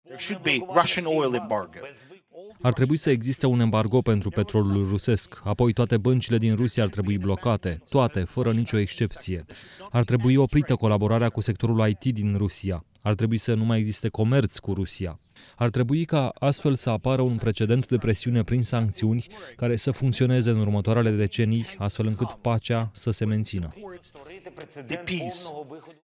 Într-o intervenție în direct, la Forumul Economic de la Davos, președintele Ucrainei, Volodymir Zelenski, le-a cerut liderilor mondiali să interzică importul de petrol din Rusia.